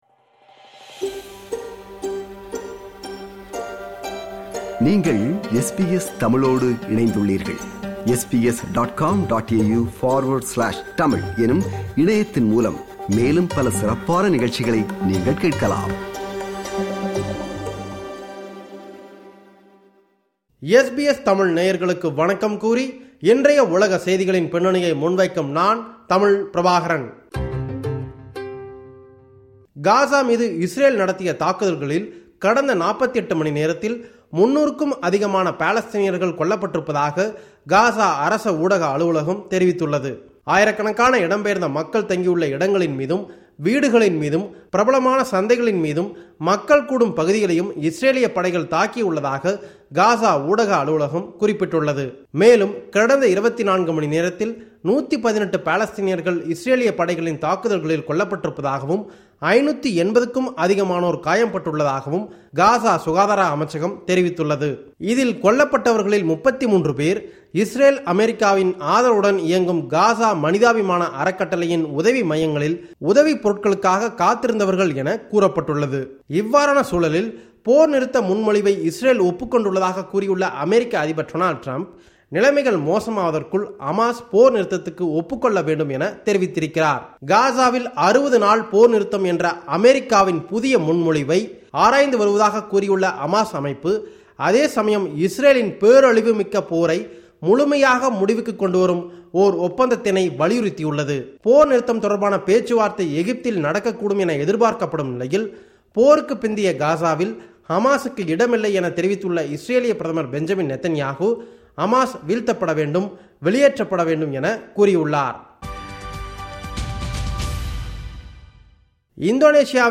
உலகச் செய்திகளின் பின்னணியை முன்வைக்கிறார் நமது தமிழக செய்தியாளர்